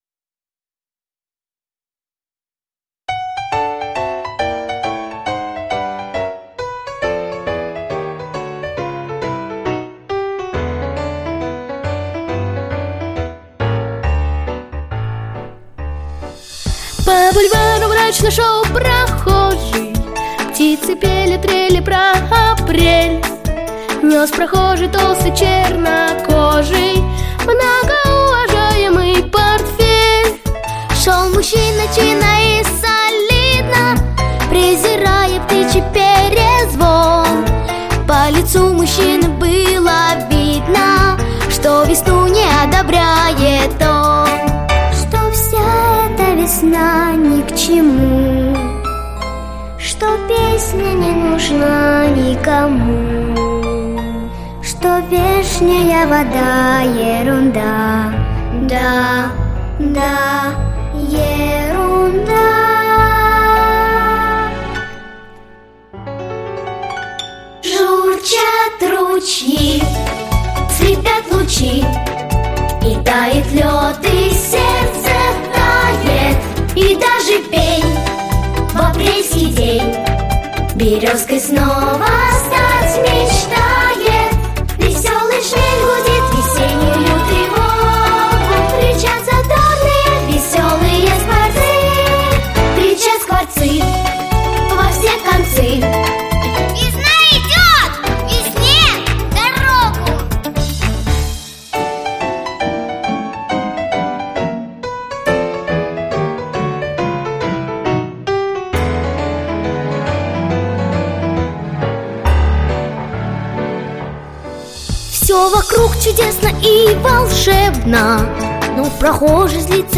Песенки про весну